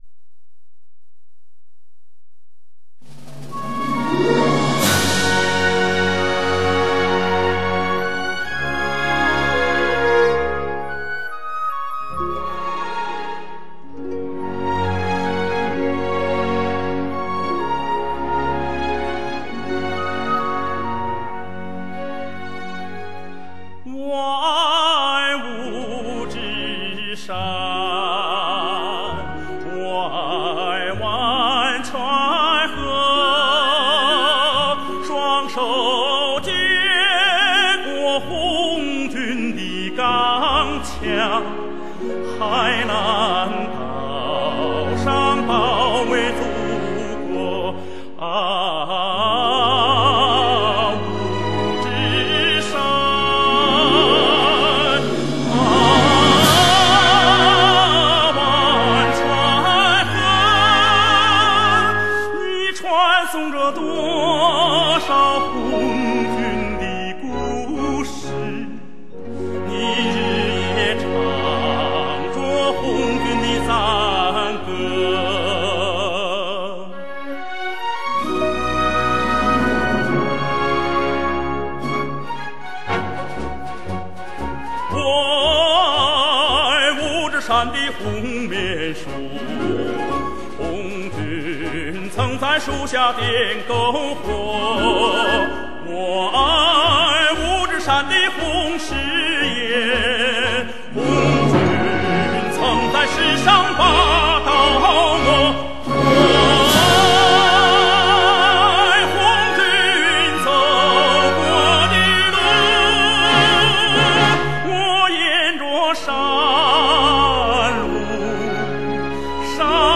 音乐类型:  民族歌曲
一份你应该拥有的艺术珍品，中国第一男高音。